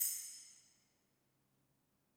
JJPercussion (12).wav